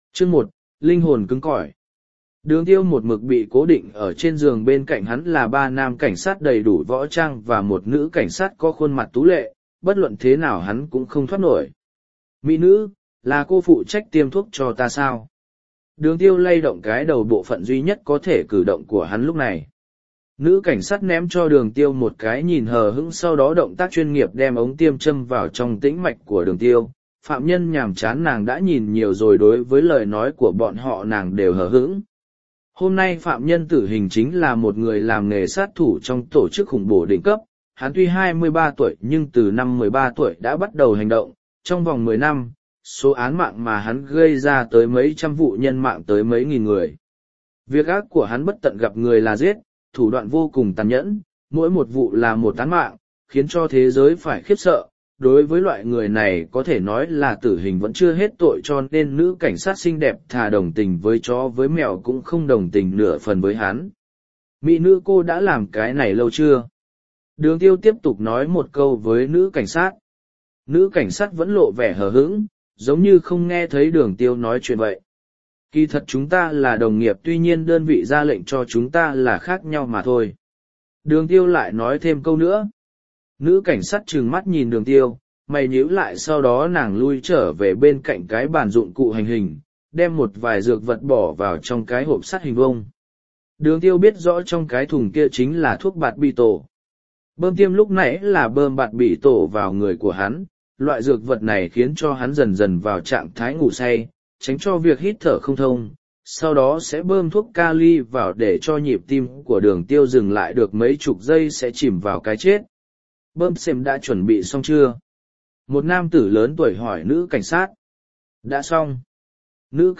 Sát Vương Audio - Nghe đọc Truyện Audio Online Hay Trên TH AUDIO TRUYỆN FULL